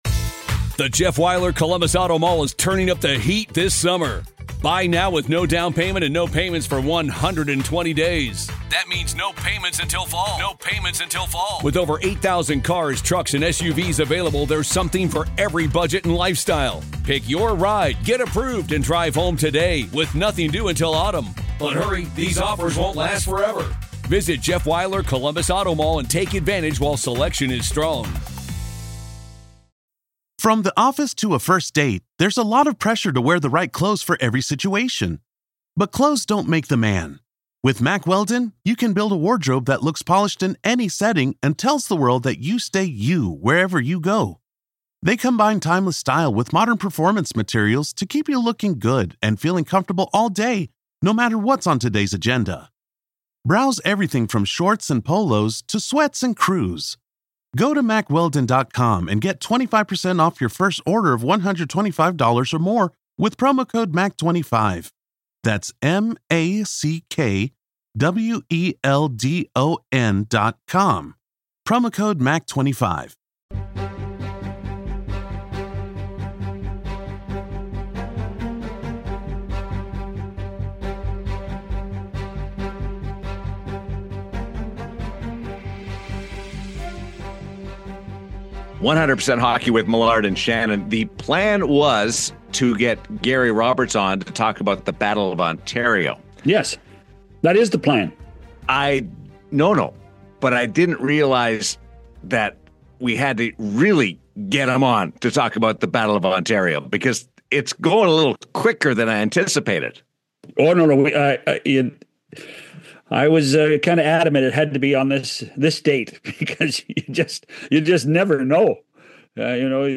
It’s a gritty, honest conversation about playoff pressure, passion, and the rivalries that define hockey’s soul.